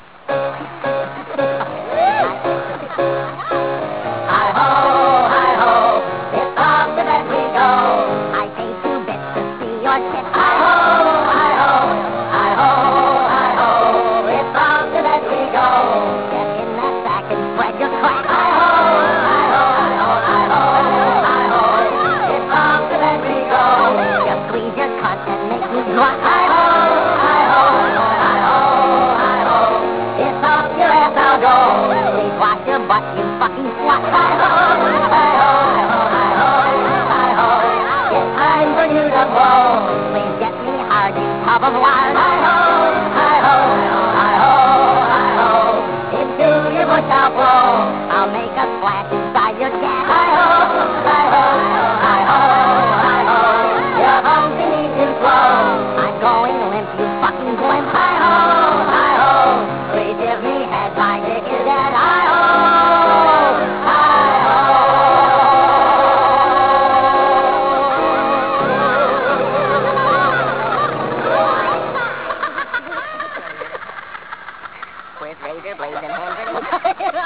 - sounds like the same guys who made 'ozsex'.